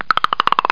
CREAK.mp3